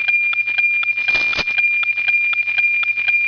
interference_noaa19.mp3